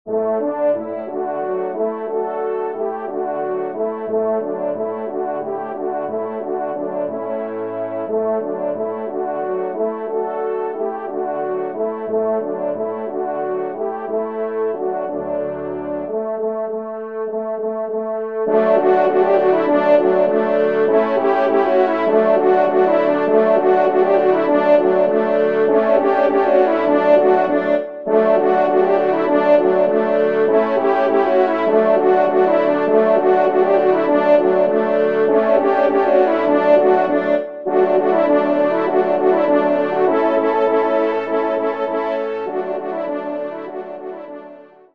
Genre :  Divertissement pour Trompes ou Cors en Ré
ENSEMBLE